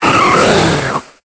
Cri d'Oniglali dans Pokémon Épée et Bouclier.